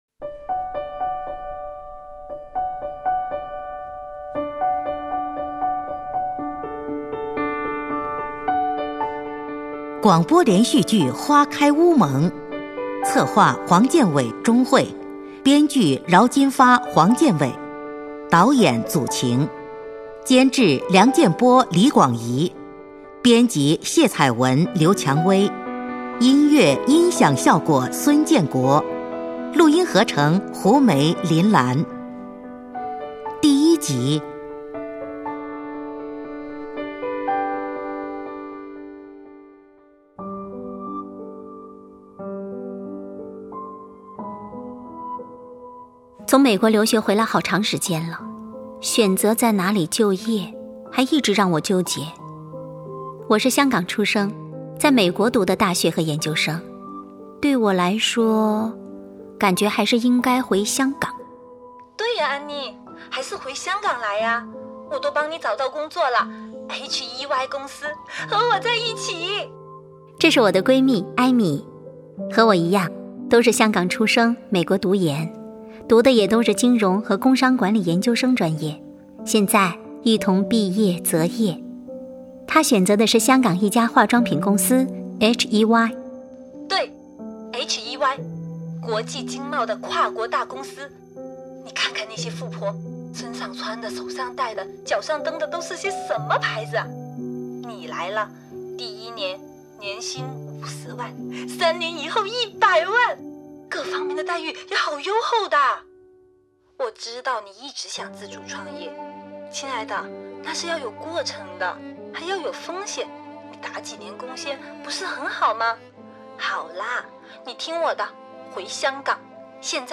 广播连续剧《花开乌蒙》第一集